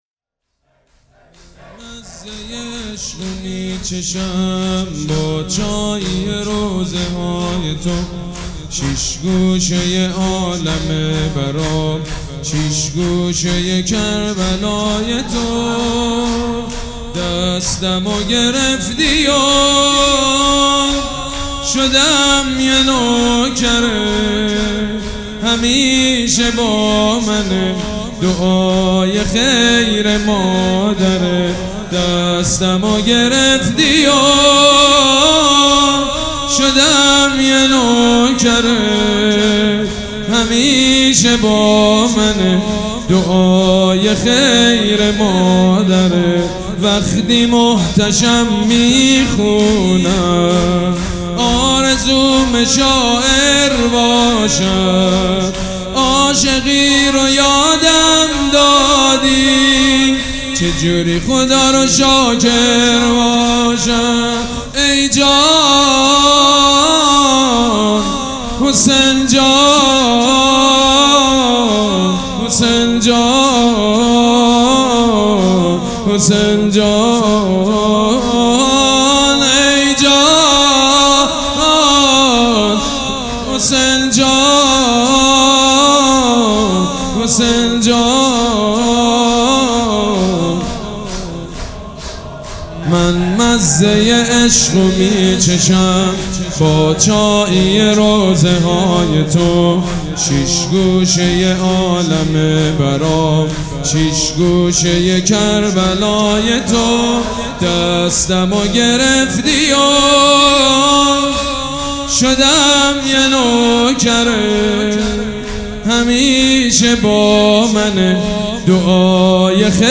شور
مداح
حاج سید مجید بنی فاطمه
مراسم عزاداری شب سوم